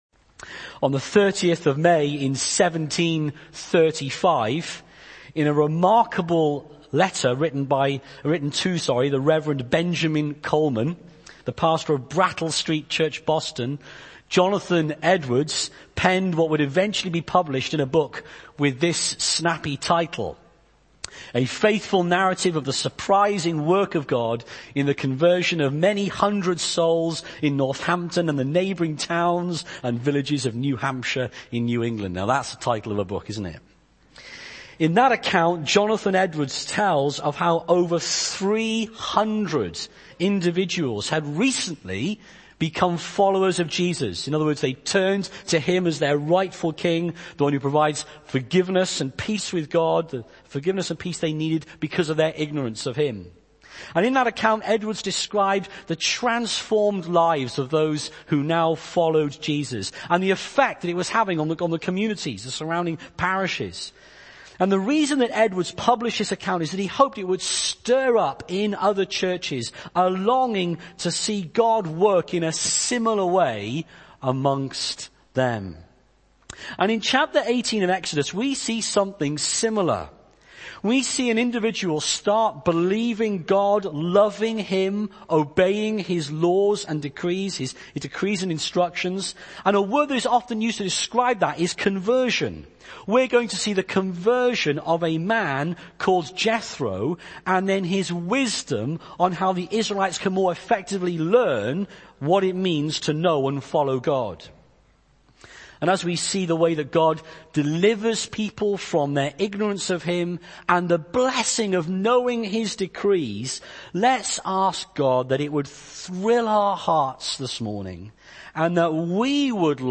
Sermons - Kensington